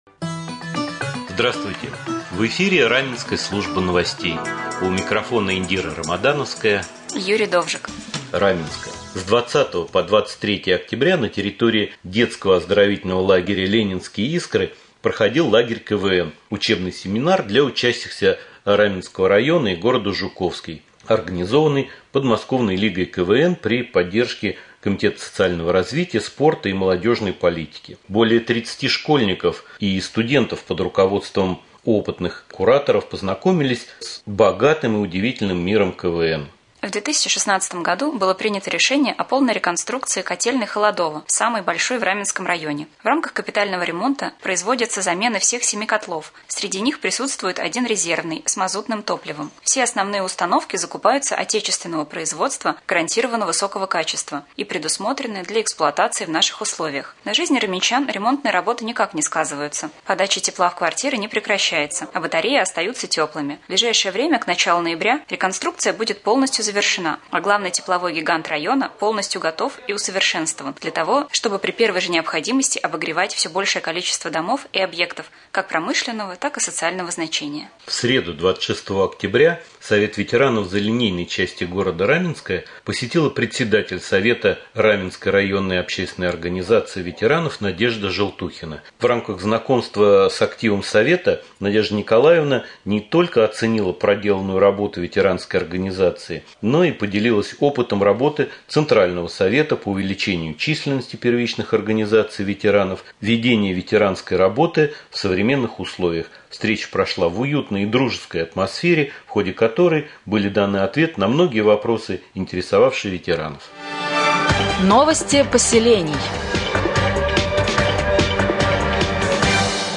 1. Новости